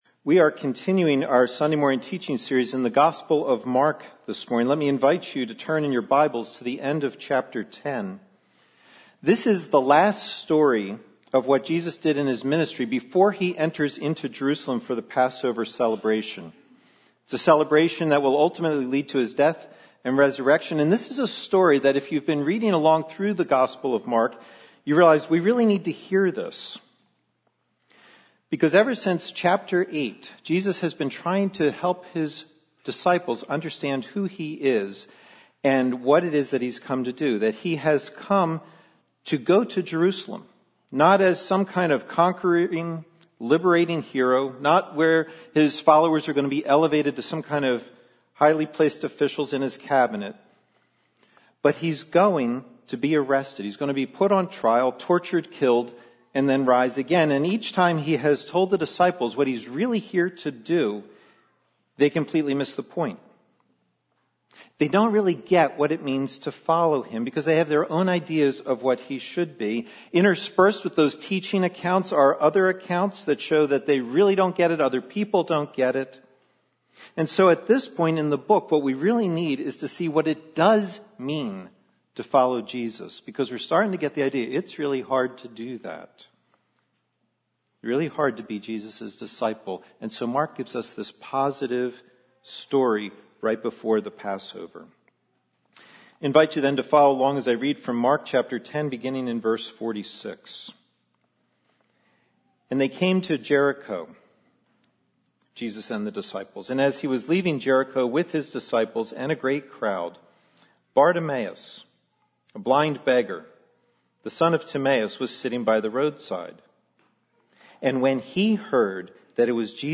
Sermons - New Life Glenside